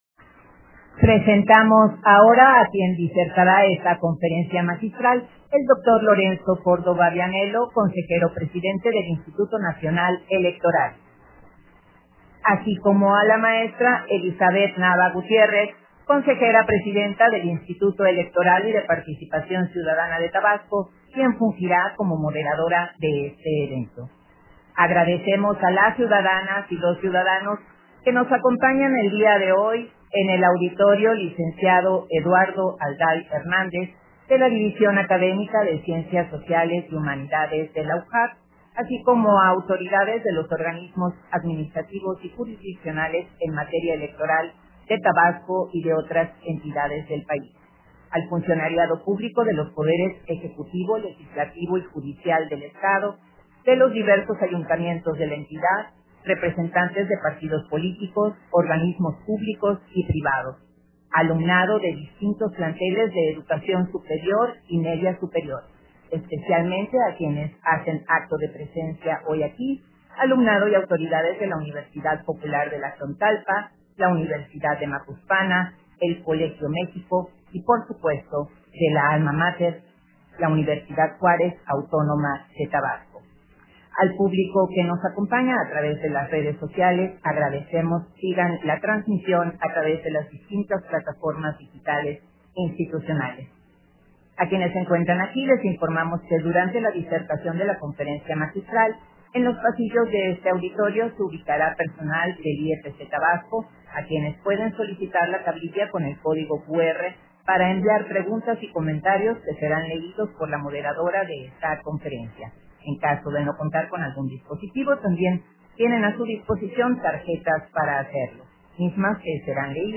Conferencia magistral, Evolución y desafíos de la Democracia mexicana, dictada por Lorenzo Córdova